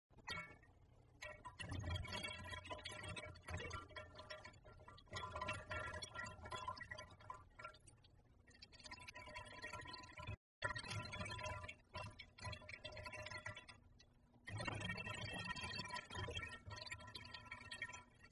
Toilet Sounds: Instant Play Sound Effect Button